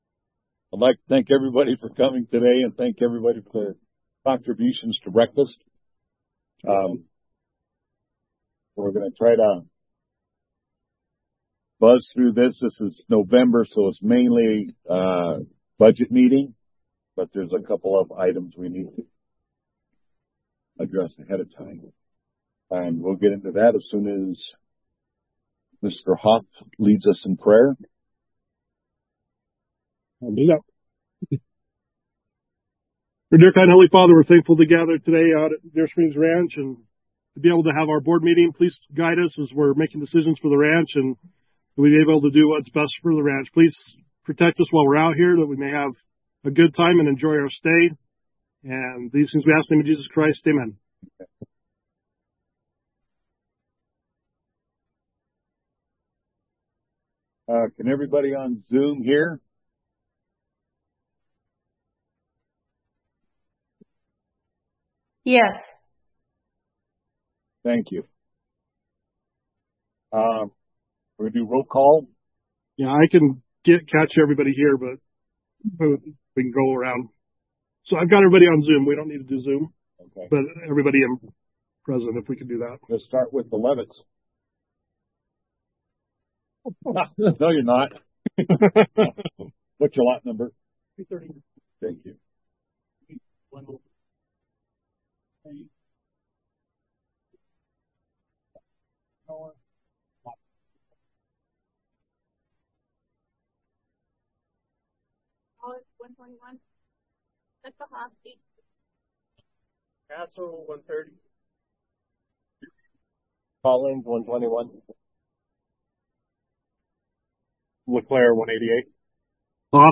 DSROA Board Meeting Audio Clips